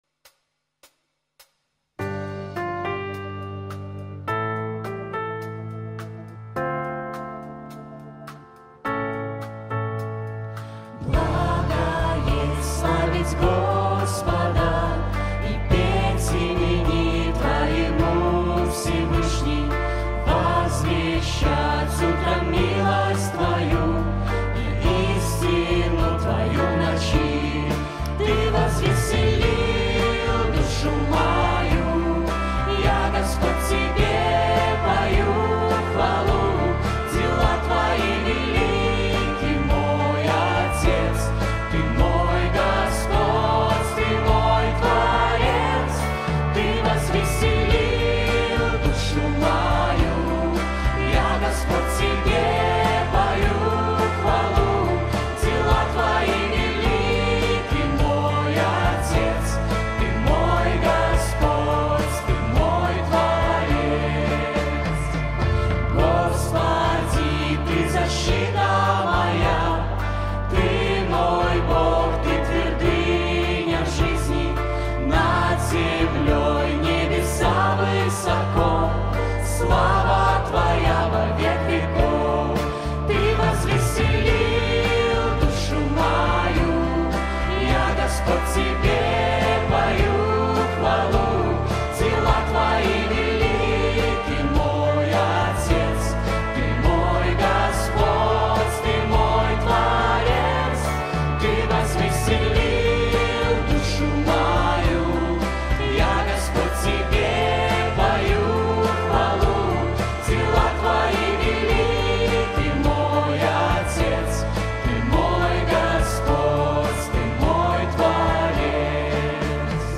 |  Пример исполнения 2 | Общее пение |